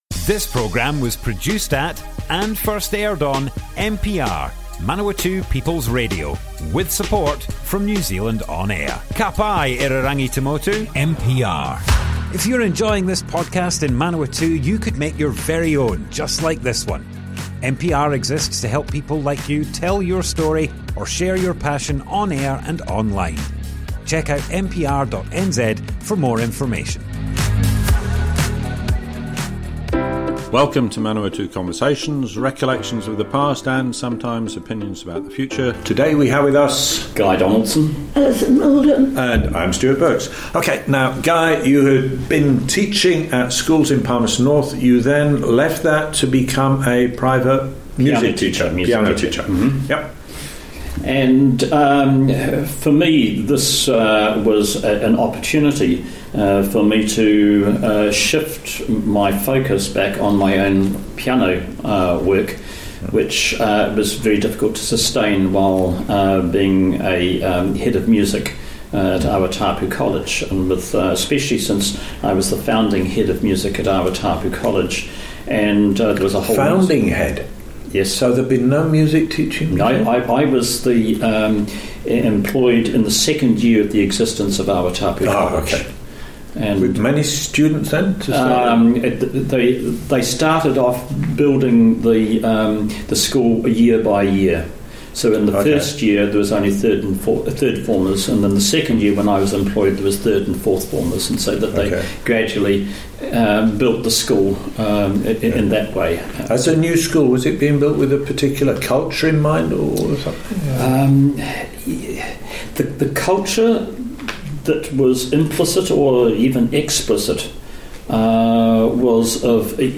Manawatu Conversations More Info → Description Broadcast on Manawatu People's Radio, 16th April 2024.